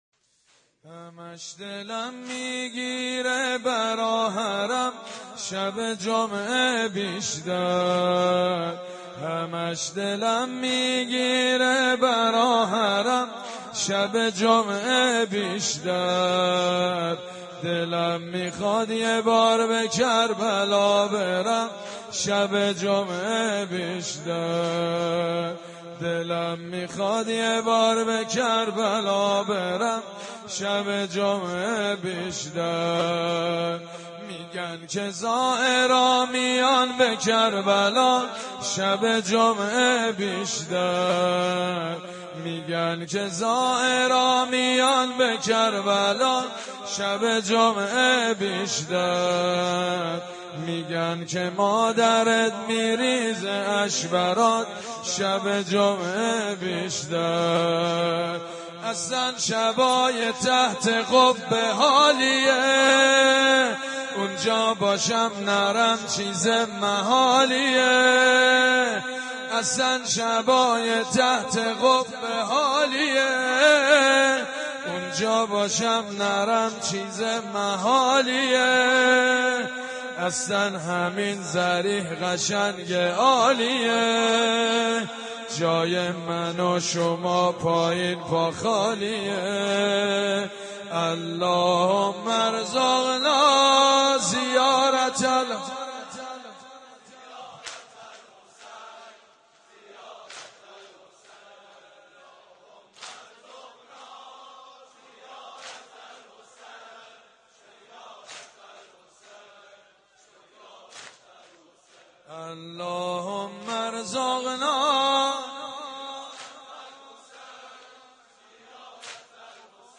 مداحی شب جمعه حرم امام حسین